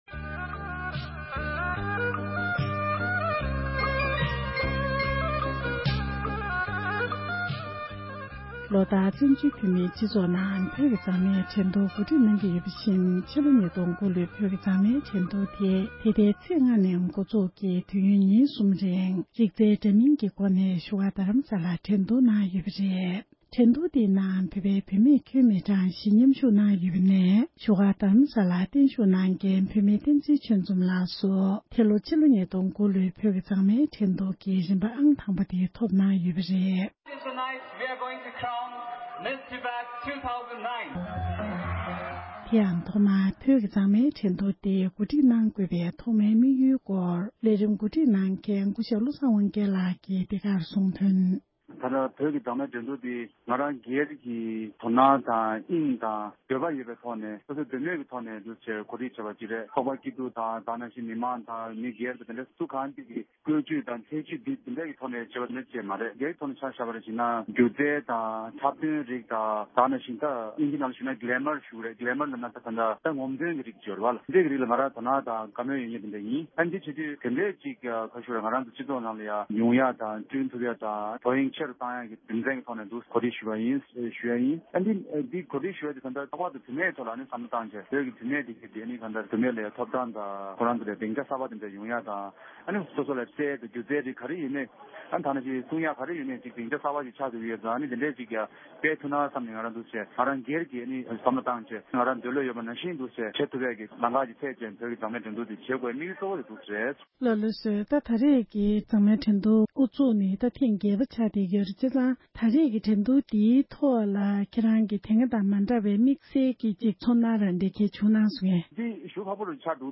འབྲེལ་ཡོད་མི་སྣར་གནས་འདྲི་ཞུས་ཏེ་ཕྱོགས་བསྒྲིགས་ཞུས་པར་གསན་རོགས༎